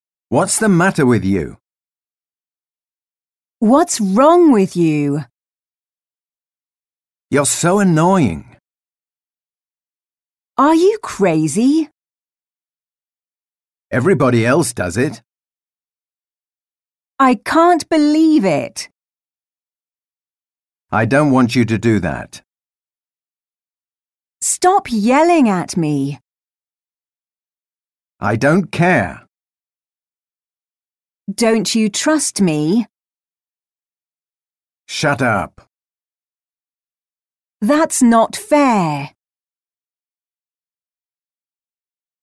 Listen and repeat the sentences expressing exasperation.